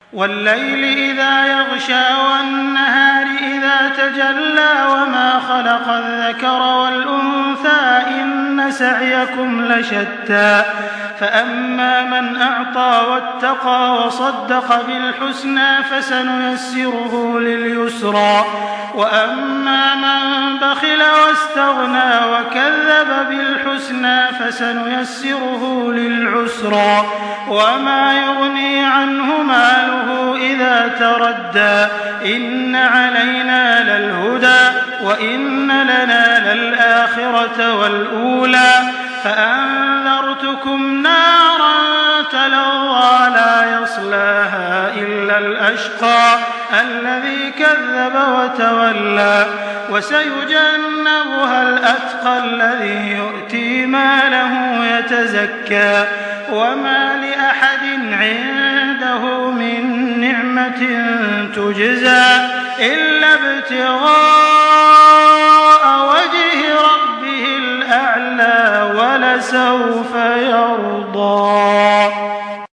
سورة الليل MP3 بصوت تراويح الحرم المكي 1424 برواية حفص عن عاصم، استمع وحمّل التلاوة كاملة بصيغة MP3 عبر روابط مباشرة وسريعة على الجوال، مع إمكانية التحميل بجودات متعددة.
تحميل سورة الليل بصوت تراويح الحرم المكي 1424